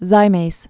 (zīmās, -māz)